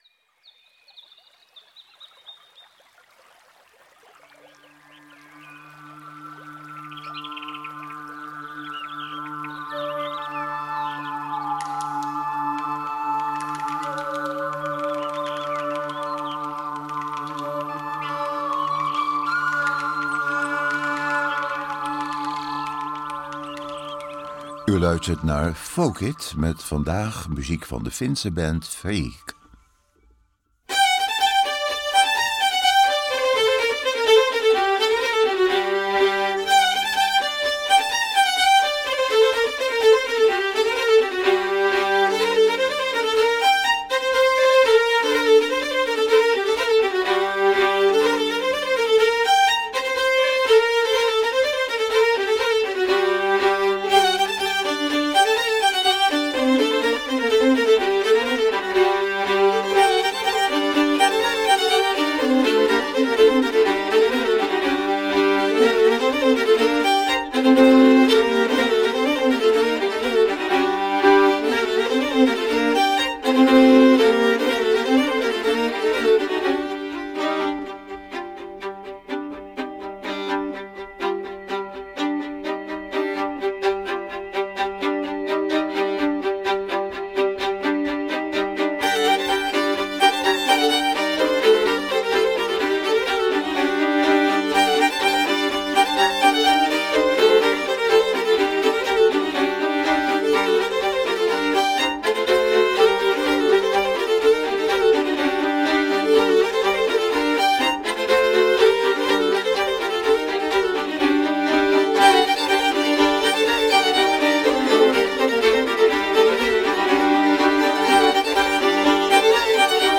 In de groep aanvankelijk 4 violen en een contrabas. In de muziek is de scandinavische viooltraditie van de speelmannen te horen.